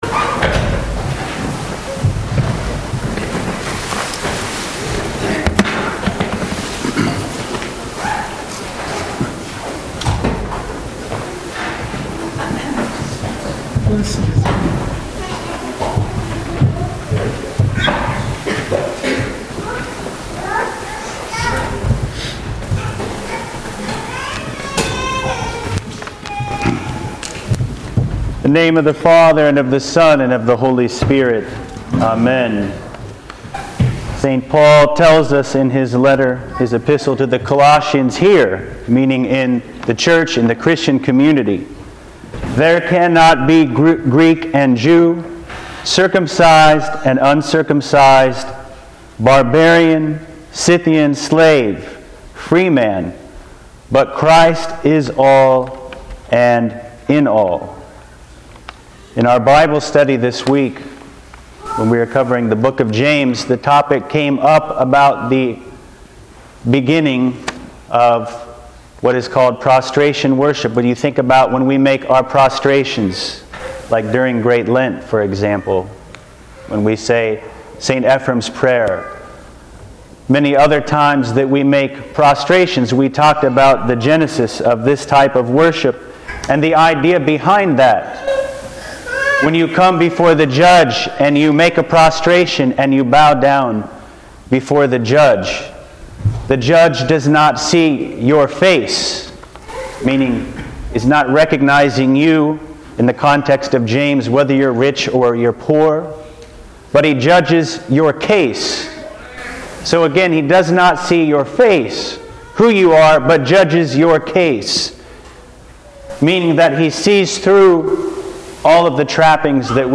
Sermons | St. Mary Orthodox Christian Church